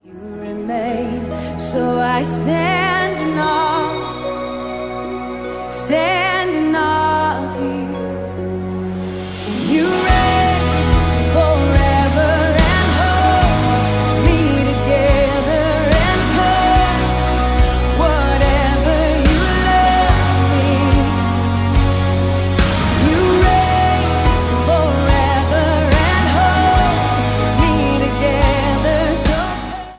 Szenvedélyes, megállíthatatlan dicsőítés